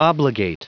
Prononciation du mot obligate en anglais (fichier audio)